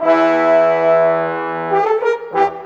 Rock-Pop 07 Brass 04.wav